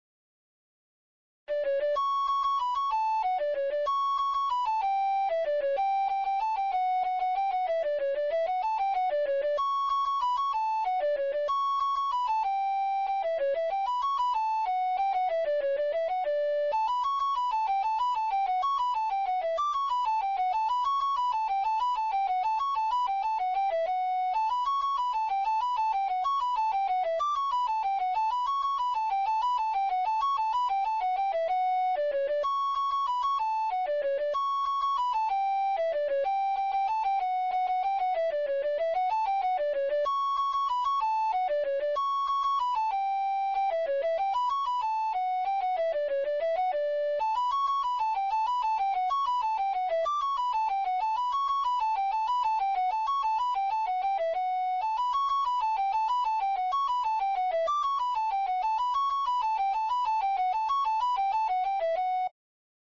Segunda voz